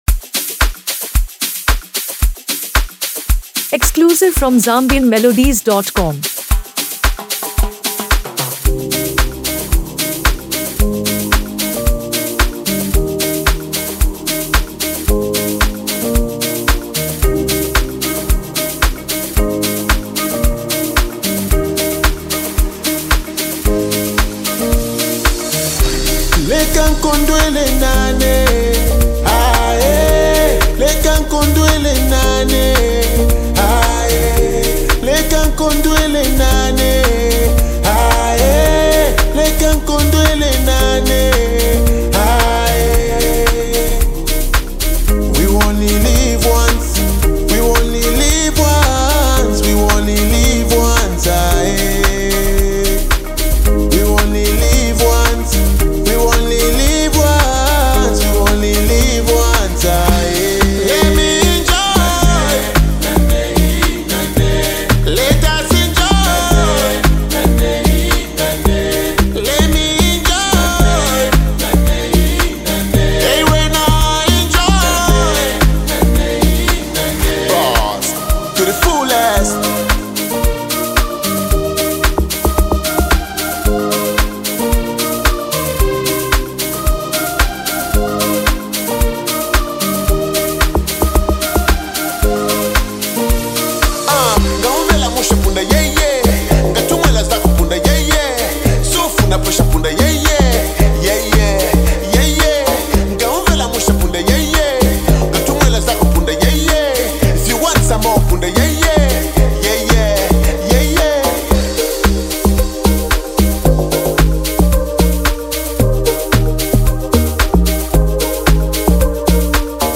a vibrant anthem about happiness, celebration, and freedom
With its groovy beats and infectious melody